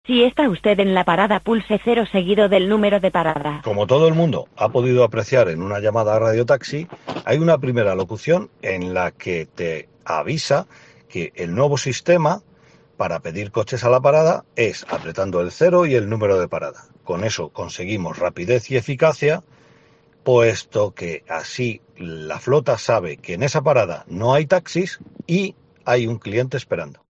Esta es la locución que vas a escuchar desde ahora cuando llames a Radio Taxi Valladolid para avisar de que no hay ningún taxi en una parada.